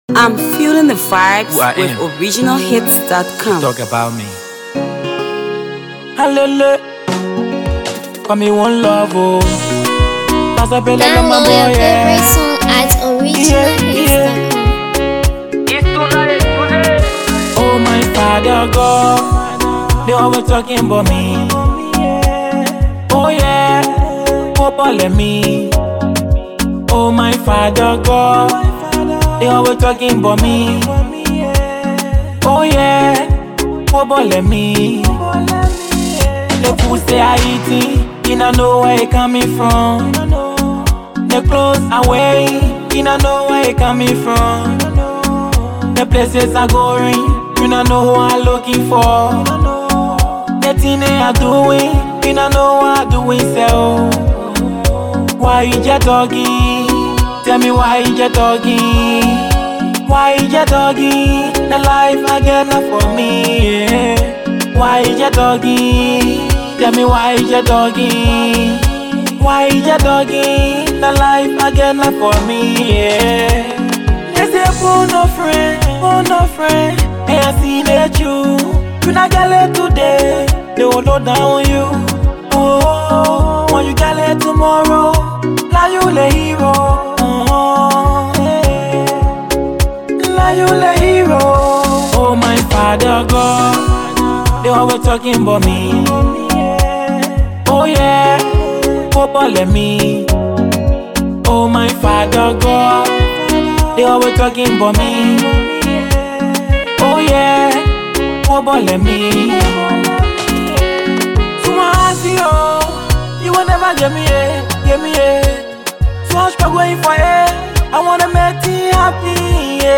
AfroAfro PopLATEST PLAYLISTMusic